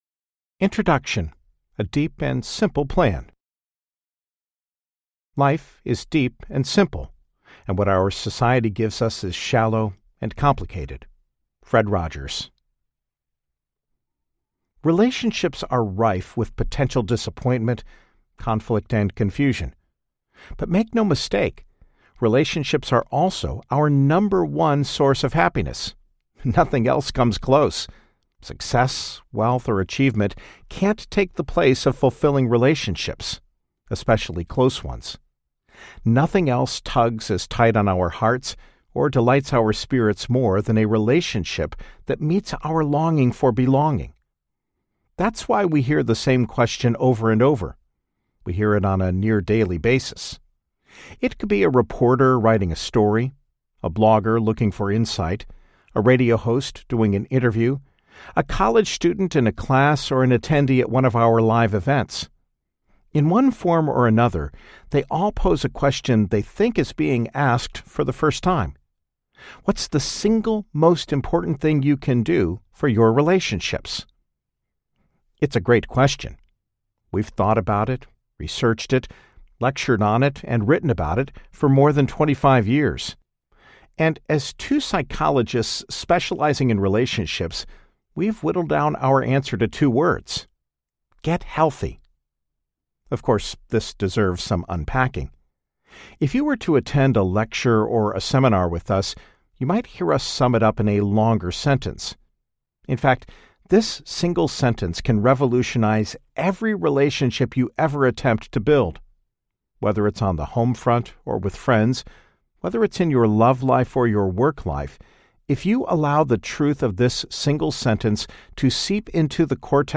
Healthy Me, Healthy Us Audiobook
4.1 Hrs. – Unabridged